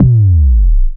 Time Freeze Sound Effect Free Download